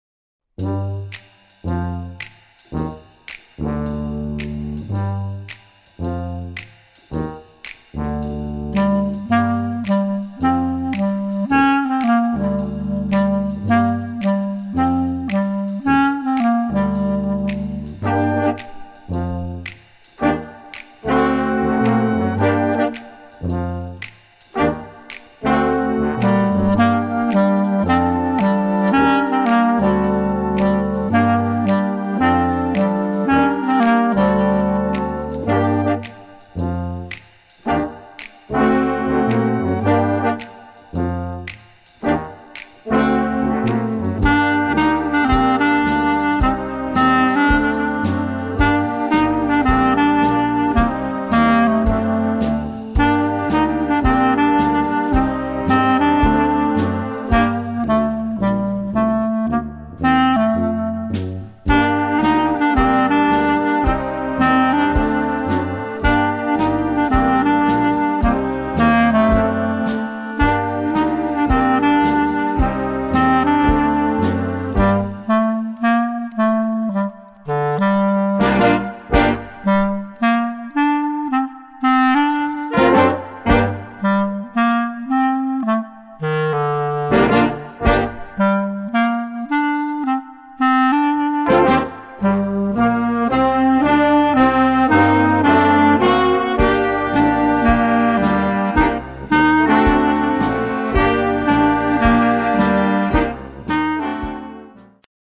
Gattung: Jugendwerk
A4 Besetzung: Blasorchester PDF